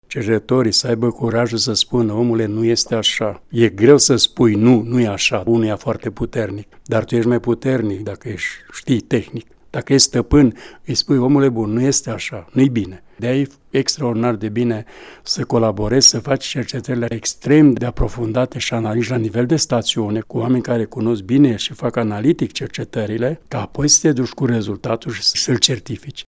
Într-o serie de interviuri